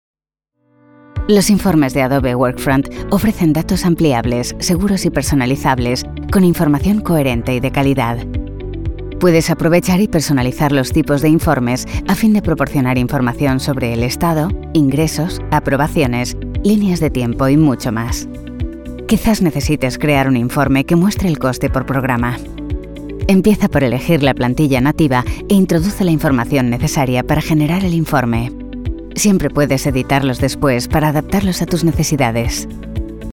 Locutora en español castellano en línea con fluidez en inglés.
Cabina de grabación insonorizada (studiobricks)
Mic Neumann U87 Ai